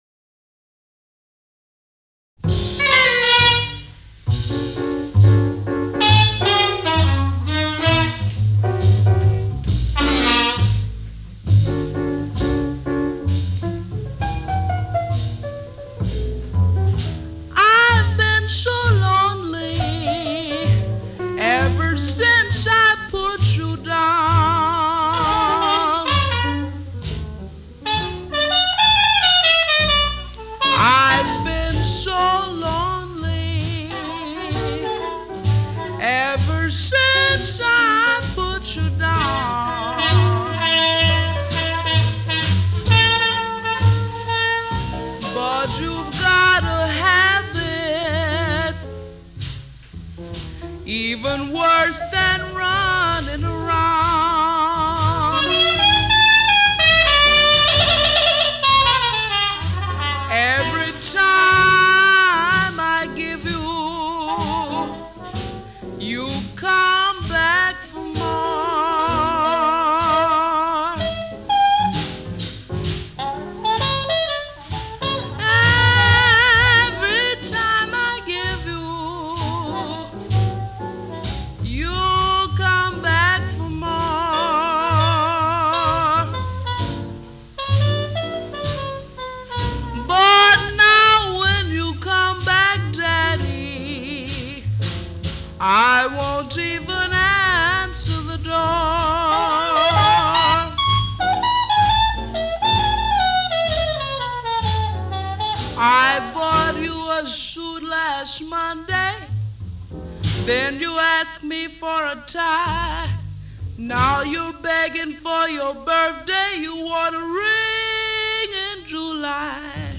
Alto Saxophone
Bass
Drums
Piano
Vibraphone
Trumpet